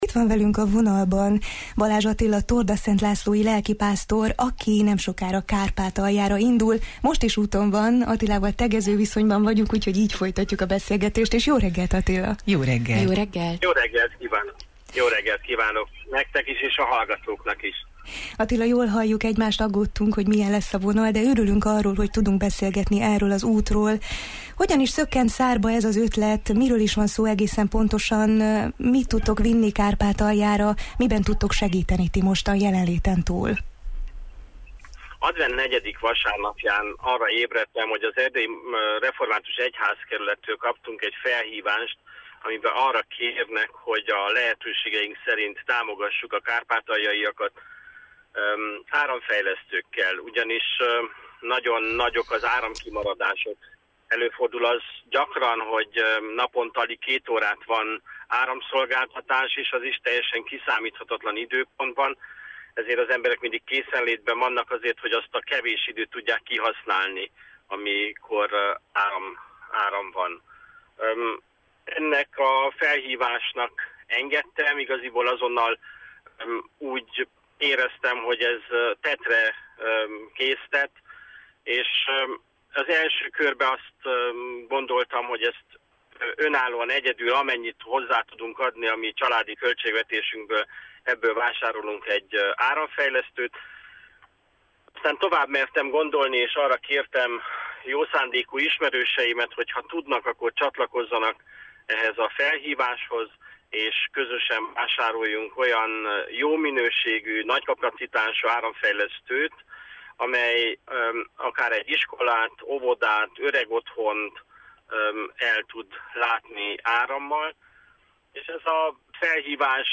A mai Jó reggelt, Erdélyben az utat megelőző előkészületekről, gyűjtési folyamatról beszélgettünk.